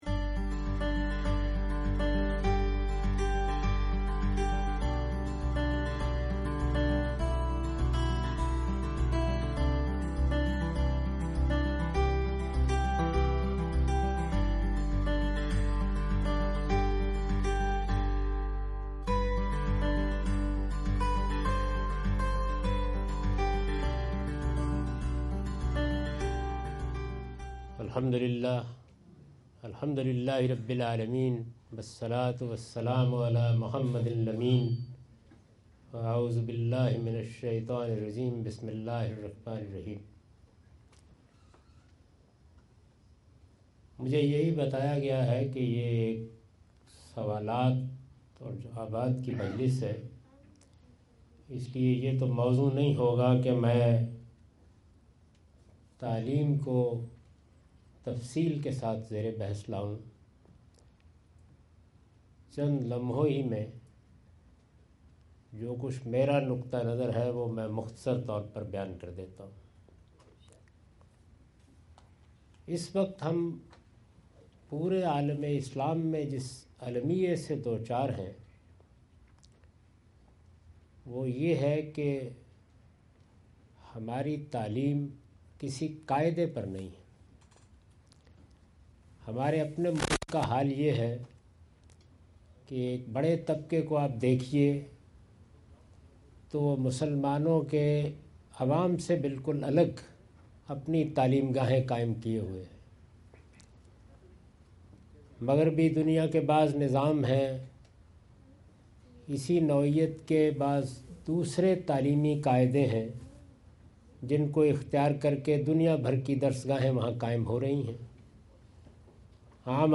Q&A Session US Tour 2017, Iqra Foundation Illinois US.
In this video Javed Ahmad Ghamidi answers the questions asked at Iqra Foundation Illinois US on September 24,2017.